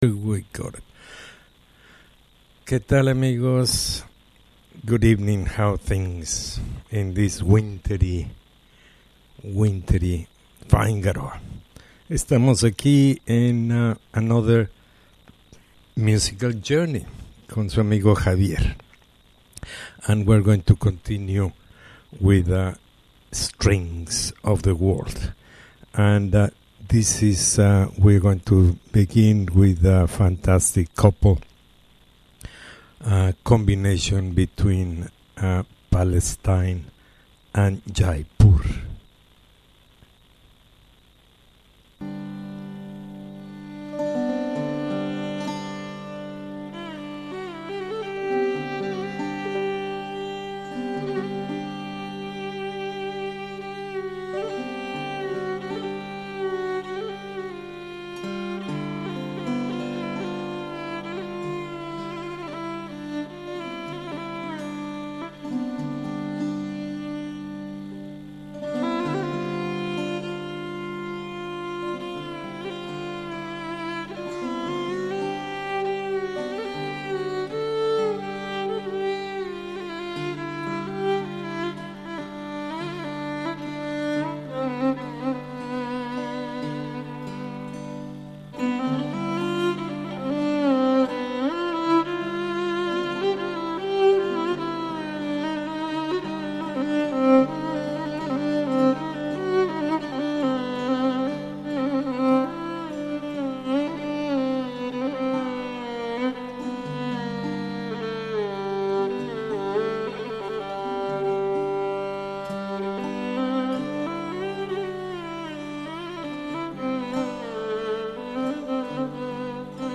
Strings from the world